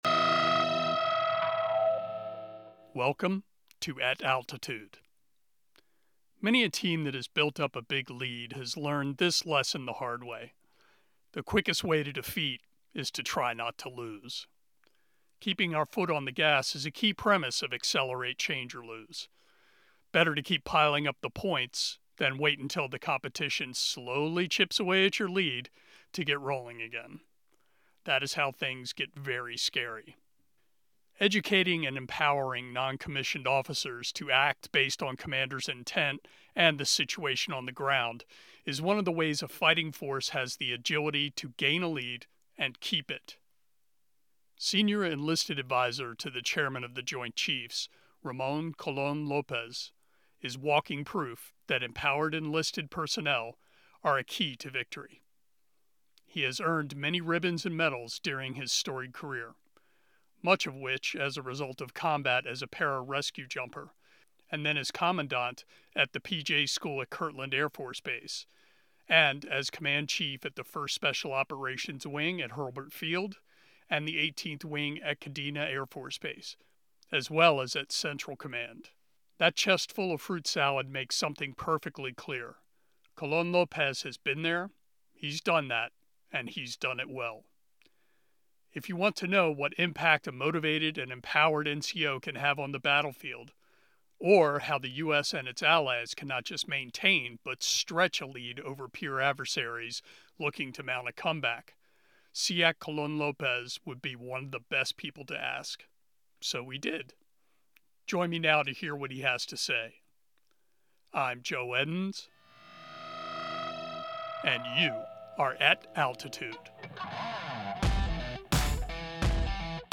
Airman magazine interview with Senior Enlisted Advisor to the Chairman of the Joint Chiefs of Staff, SEAC Ramon Colon-Lopez. After a storied career in joint special operations, Colon-Lopez is in a unique position to advise on the military's enlisted personnel and promote the warfighting benefit of NCO's empowered to make operational decisions in the battlespace.